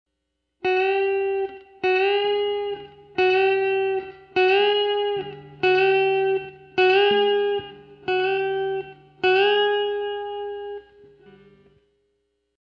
The first bend is a bend of 1/2 step on the second string 7th fret.
The second bend is a bend of 1 step on the second string 7th fret.
The Basic Bend